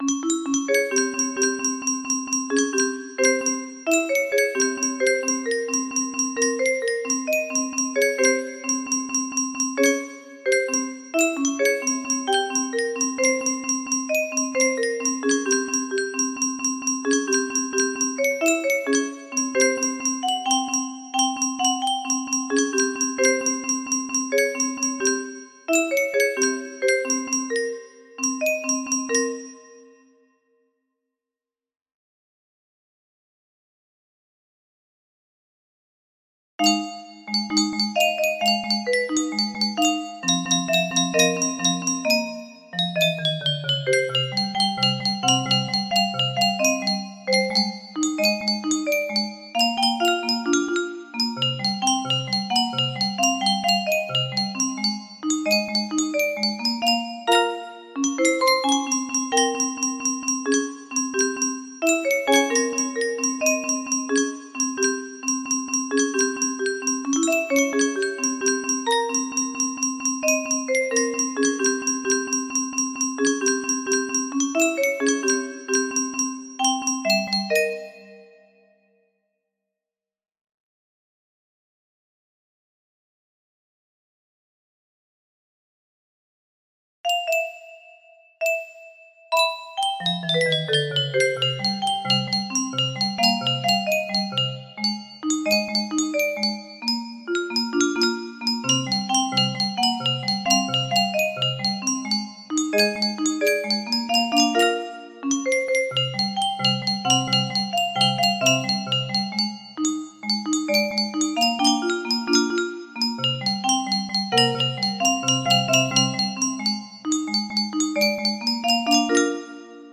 Unknown Artist - Untitled music box melody
Full range 60
Imported from MIDI from imported midi file (18).mid